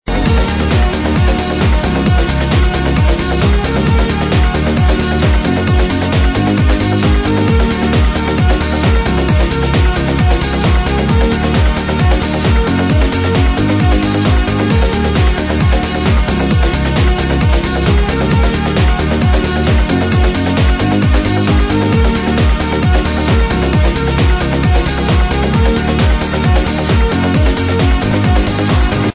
nice progressive track with folk melodie